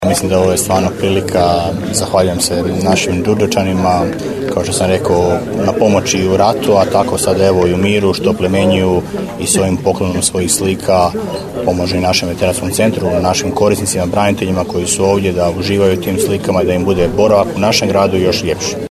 Ovom prilikom gradonačelnik Damir Lneniček zahvalio se Đurđevčanima za pomoć Daruvaru i u prošlosti i u sadašnjosti